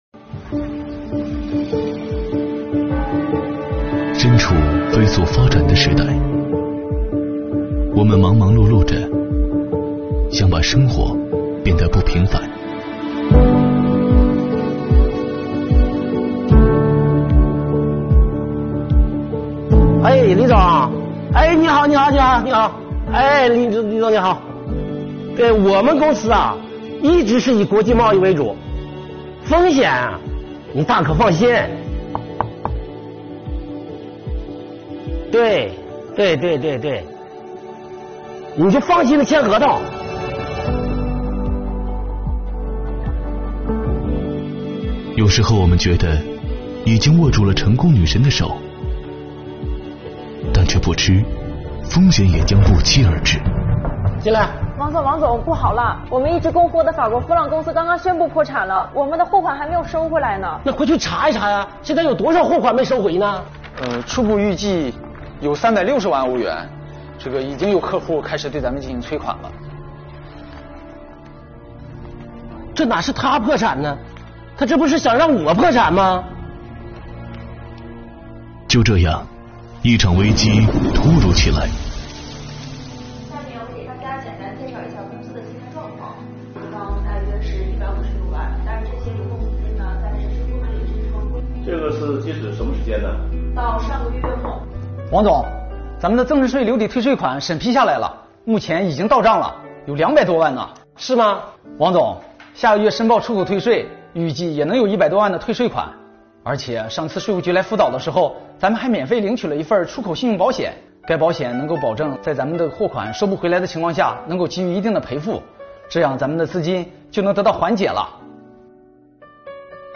作品通过人物演绎+场景还原的情景短剧形式，生动讲述了某外贸公司面临破产，最后在税务部门精细帮扶下走出困境的故事，展示了税务部门多维度助力企业纾困解难、提高企业抗风险能力的工作实绩。